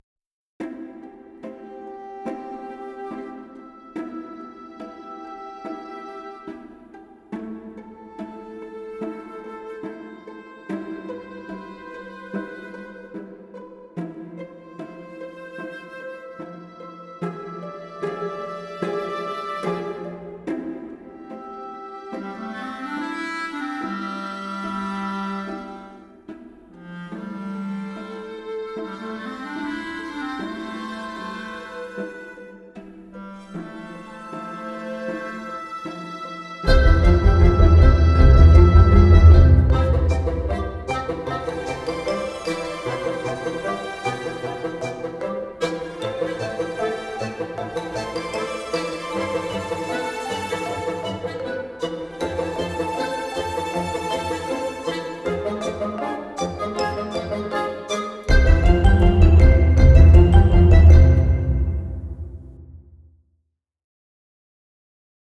Suspense Comedy